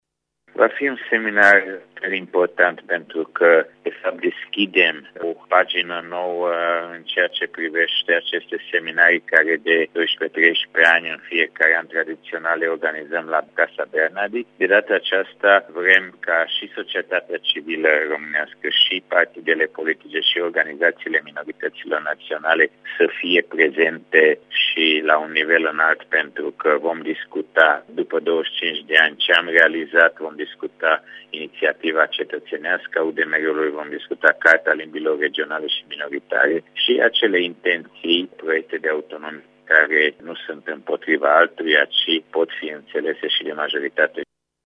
Evenimentul își propune eliminarea unor interpretări eronate ale aspiraţiilor comunităţilor minoritare, a explicat președintele Fundației, Borbély Lászlo: